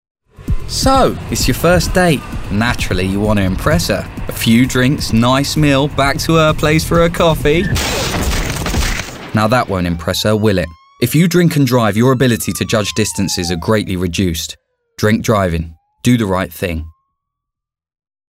LONDON / STREET / RP / ASIAN.
His voice range is late teens through to 30s, and deliveries from London urban to RP, as well as conversational Punjabi and some Middle & Far Eastern accented-English.